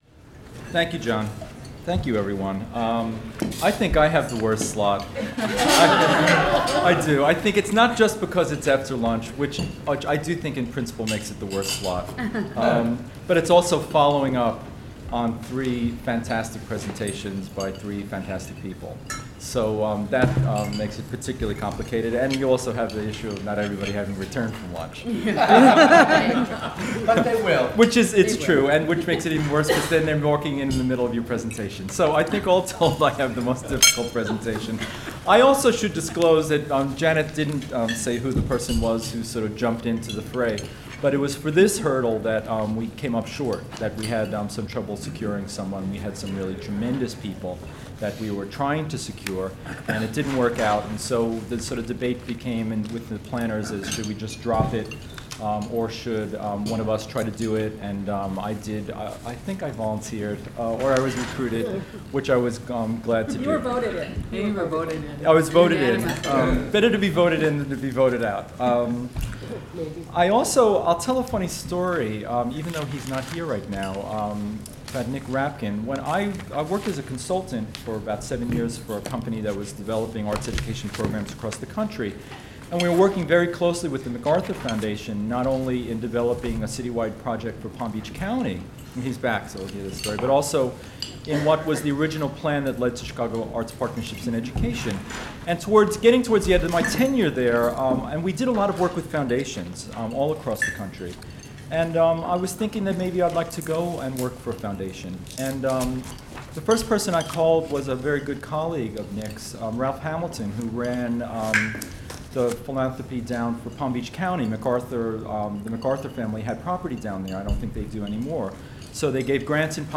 Inc. This first in a series of Grantmakers in the Arts Thought Leader Forums was held on June 24, 2010 at the Marriott Waterfront Hotel in Baltimore, Maryland.